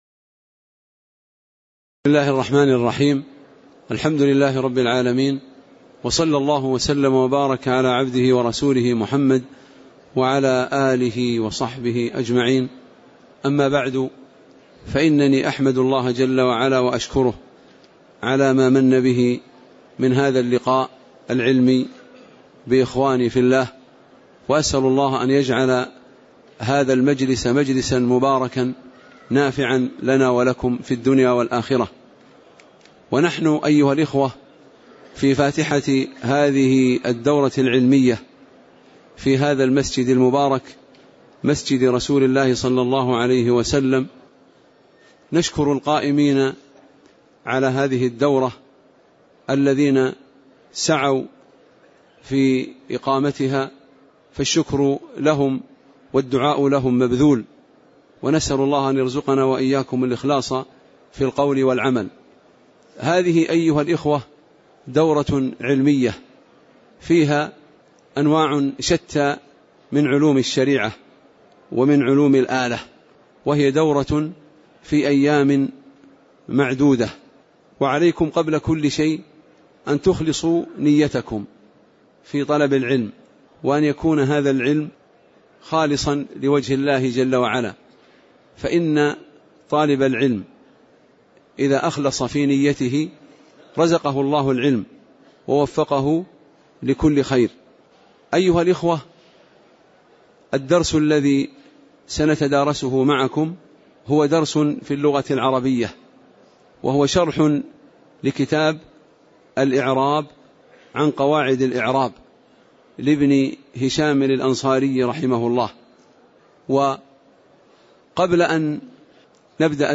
تاريخ النشر ٧ شوال ١٤٣٨ هـ المكان: المسجد النبوي الشيخ